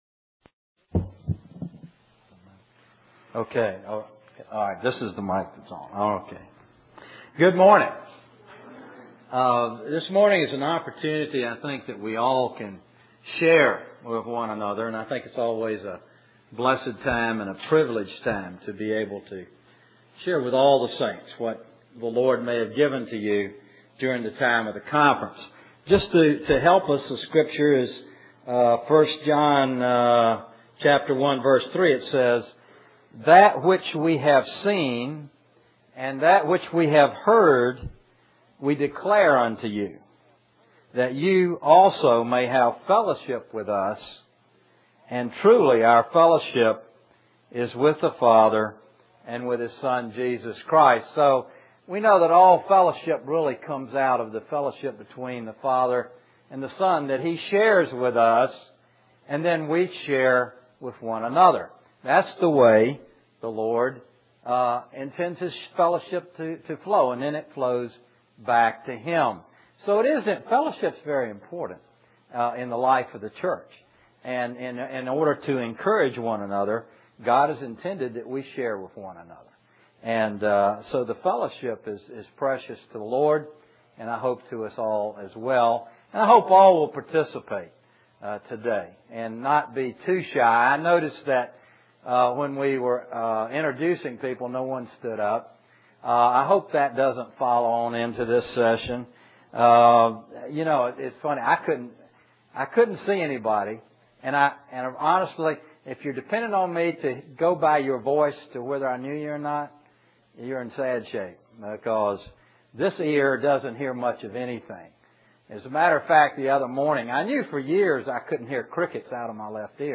Testimonies From The 2002 Christian Family Conference Various July 9th, 2002 Richmond, Virginia, US Stream or download mp3 Summary This message is a collection of testimonies offered by some saints in Richmond, Virginia, following the 2002 Christian Family Conference.